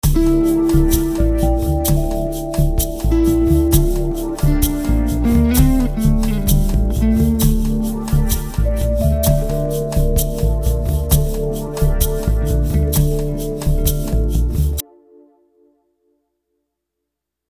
I recorded in Cubase SX, and dragged them directly from the pool's audio directory.
I started extremely electronic 130bpm, and ended up extremely acoustic leaning towards 65bpm :smile: Curious to see what people do with these.
There's an example mp3 of all the files playing at once, no effort to mix or produce, here: